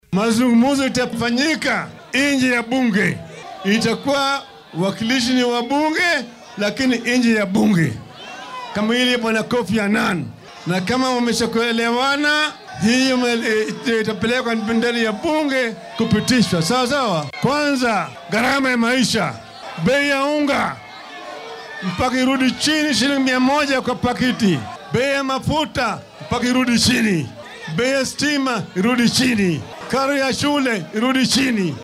Madaxa isbeheysiga mucaaradka ee Azimio La Umoja-One Kenya Raila Odinga oo shalay isku soo bax siyaasadeed ku qabtay fagaaraha Kamukunji grounds ee ismaamulka Nairobi ayaa sheegay inay diyaar u yihiin inay wada hadal la yeeshaan dowladda balse ay jiraan shuruudo arrinkan la xiriira.